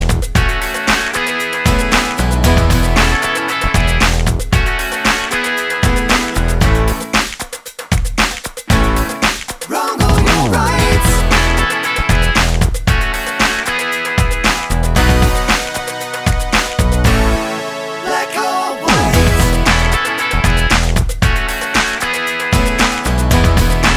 With Rapper Pop (1980s) 3:14 Buy £1.50